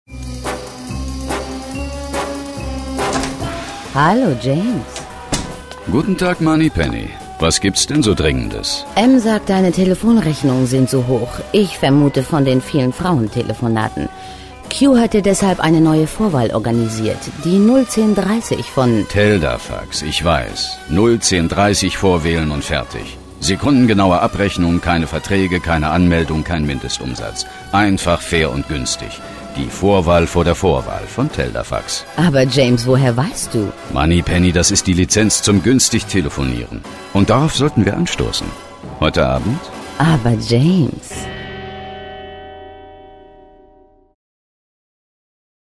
Kein Dialekt
Sprechprobe: Werbung (Muttersprache):
female voice over talent german